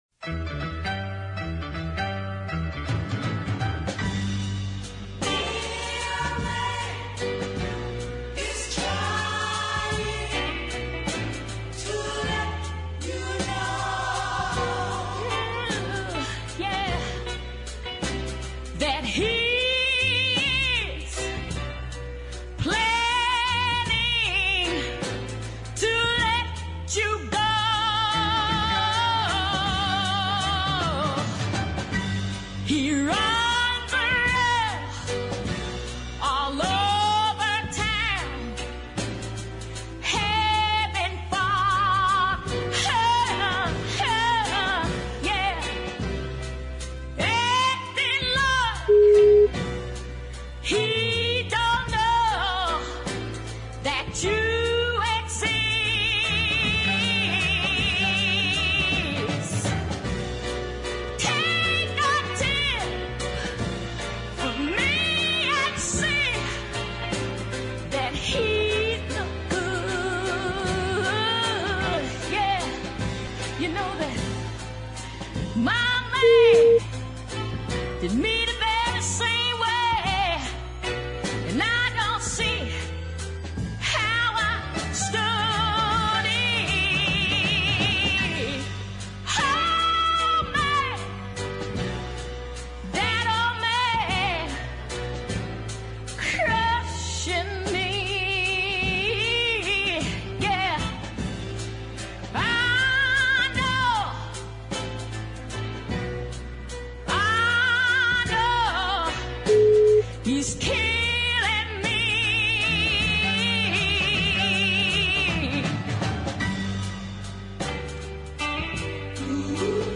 powerhouse deep track